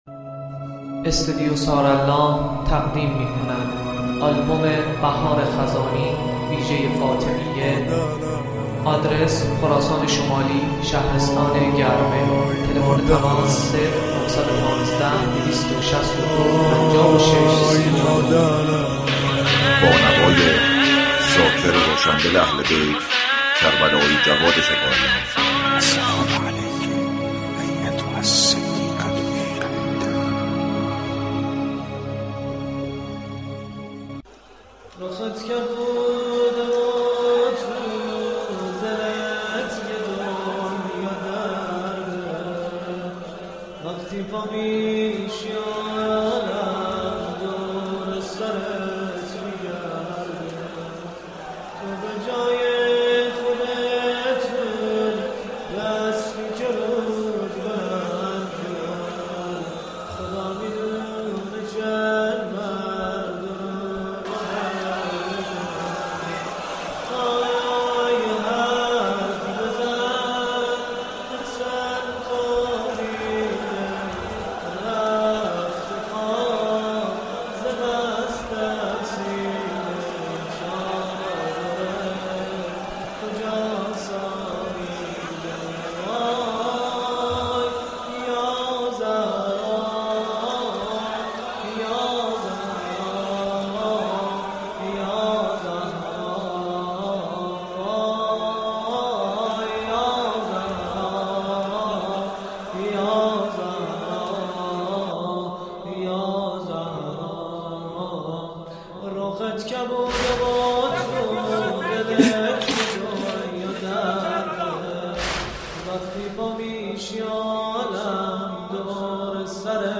مداحی ویژه ایام فاطمیه + صوت